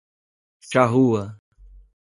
Pronunciado como (IPA) /ʃaˈʁu.ɐ/